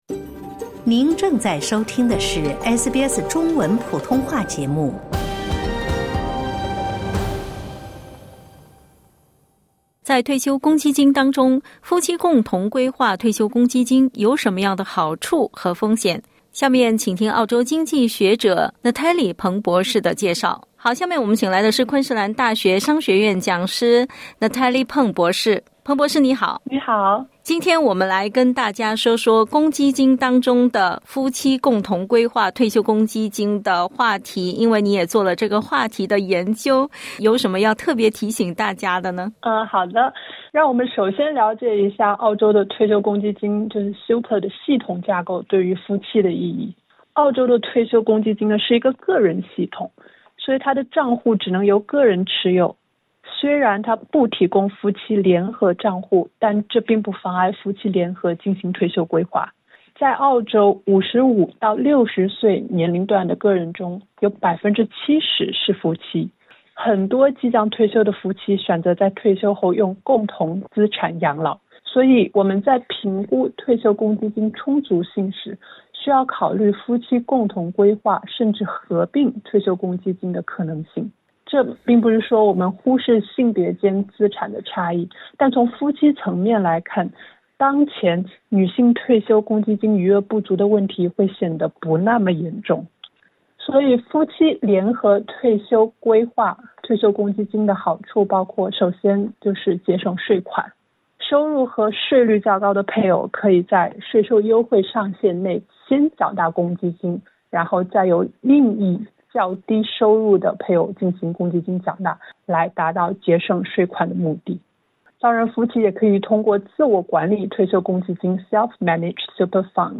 (点击音频收听详细采访） 本节目为嘉宾观点，仅供参考。